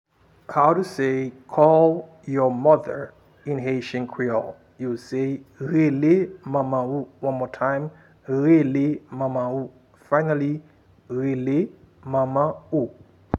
Pronunciation and Transcript:
How-to-say-call-your-mother-in-haitian-creole-Rele-manman-ou-pronunciation.m4a